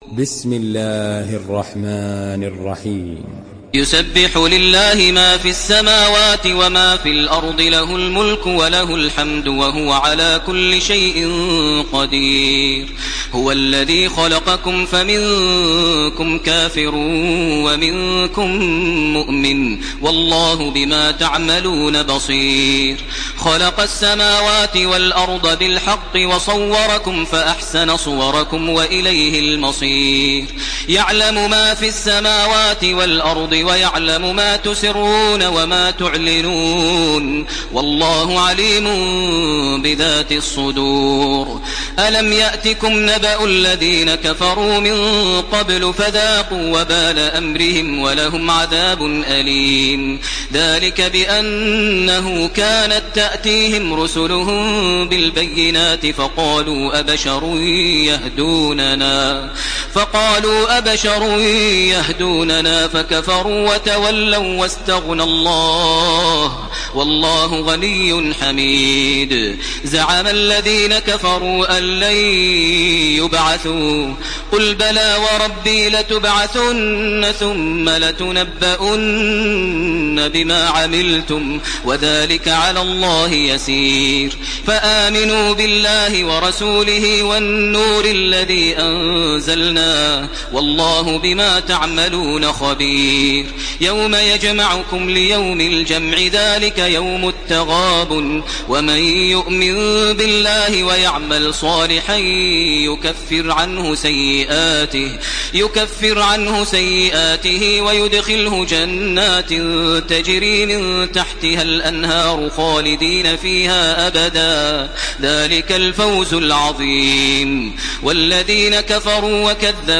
Surah At-Taghabun MP3 in the Voice of Makkah Taraweeh 1431 in Hafs Narration
Surah At-Taghabun MP3 by Makkah Taraweeh 1431 in Hafs An Asim narration.
Murattal